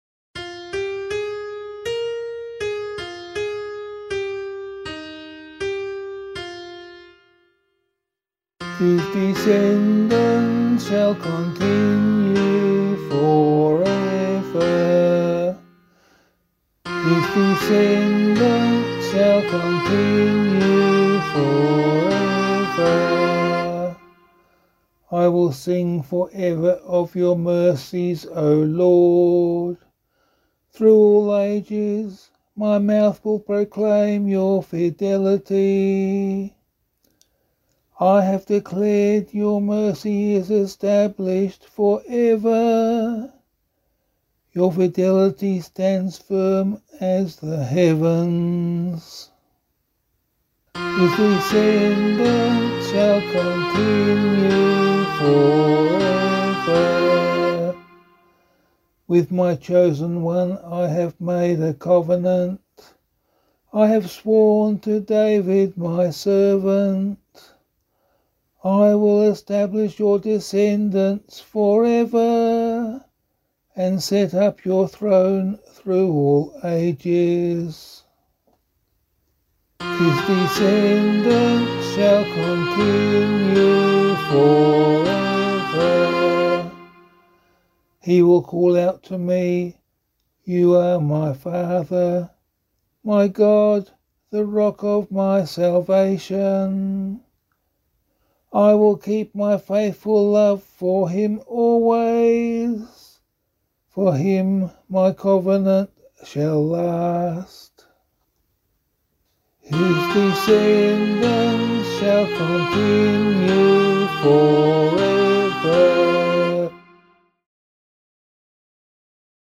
178 Joseph Psalm [APC - LiturgyShare + Meinrad 2] - vocal.mp3